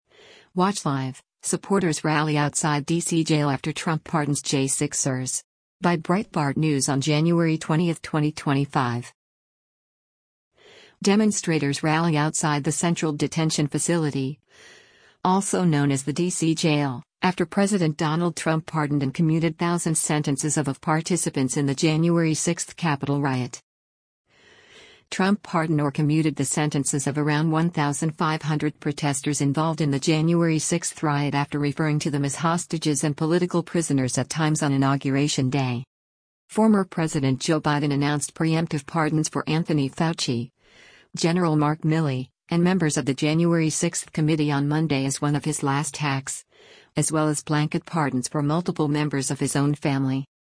Demonstrators rally outside the Central Detention Facility, also known as the D.C. Jail, after President Donald Trump pardoned and commuted thousands sentences of of participants in the January 6 Capitol riot.